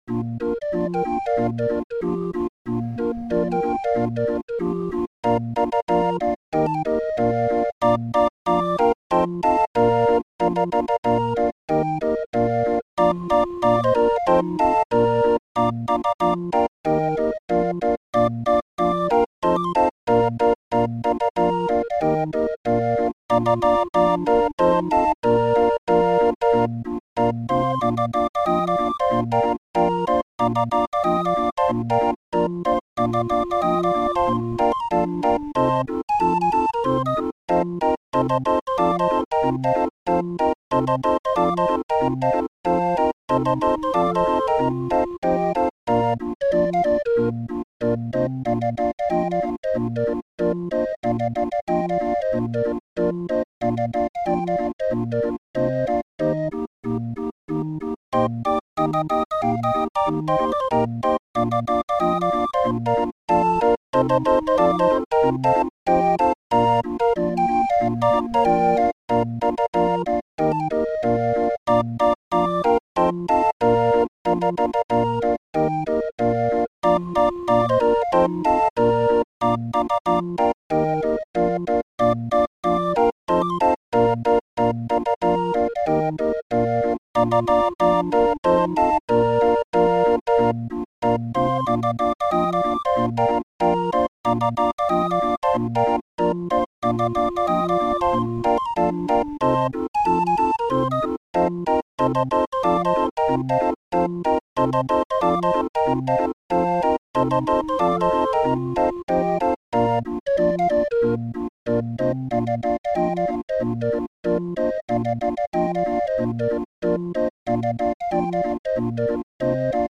Muziekrol voor Raffin 31-er